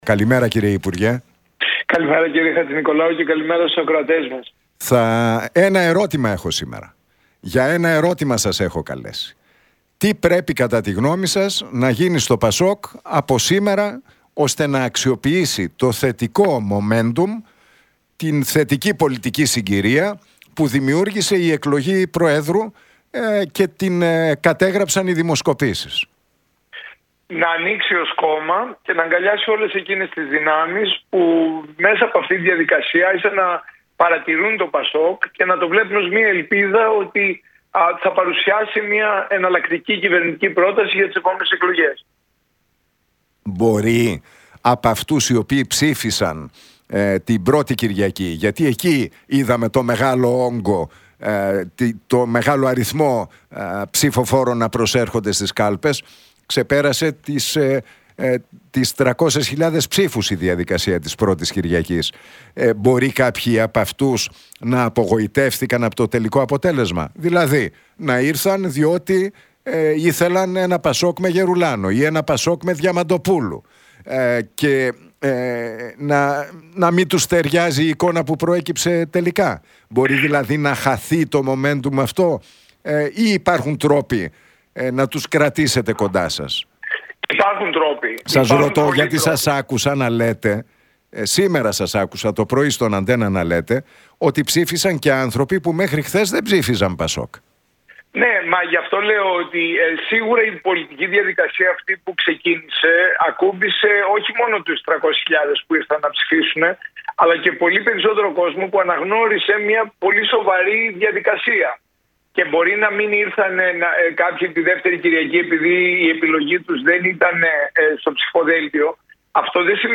Για τις εσωκομματικές κάλπες, την επόμενη μέρα στο ΠΑΣΟΚ και τις αλλαγές που πρέπει να κάνει το κόμμα στην αντιπολιτευτική τακτική του μίλησε ο Παύλος Γερουλάνος στον Νίκο Χατζηνικολάου από τη συχνότητα του Realfm 97,8.